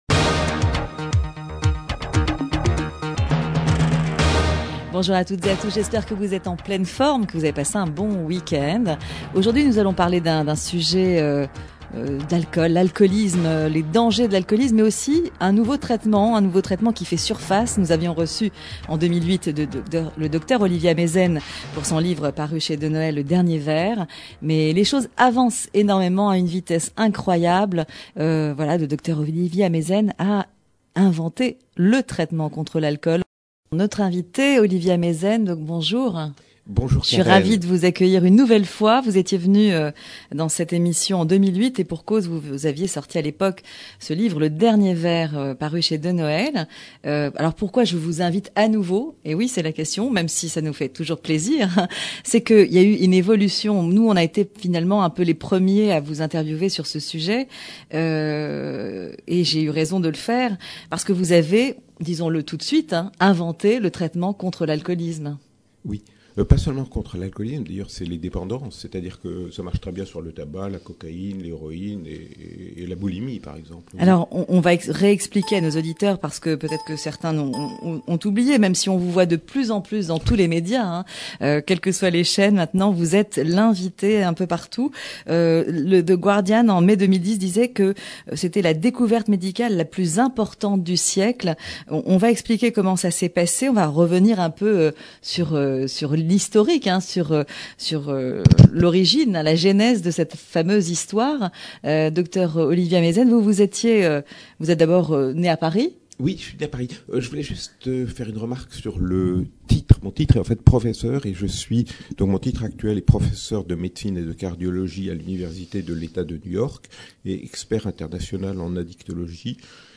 Émission médicale
Invité : Le docteur Olivier Ameisen, auteur du livre « Le dernier verre », paru aux Éditions Denoël (octobre 2008).